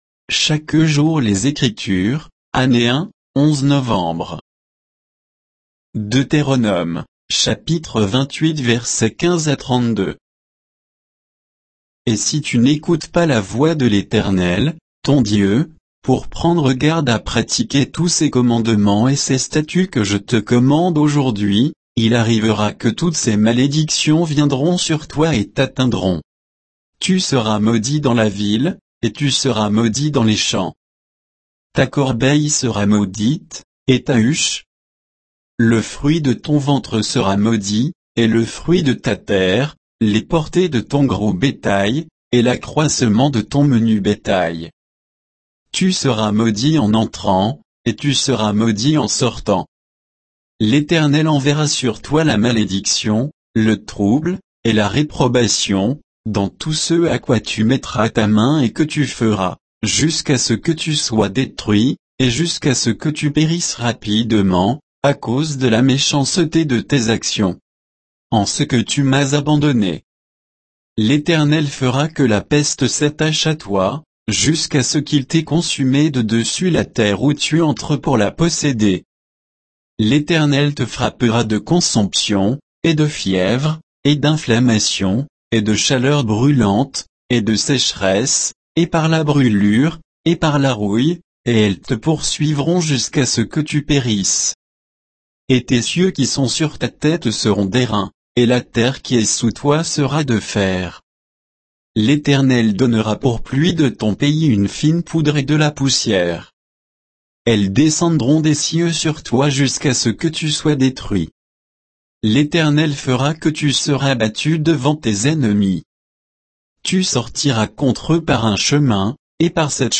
Méditation quoditienne de Chaque jour les Écritures sur Deutéronome 28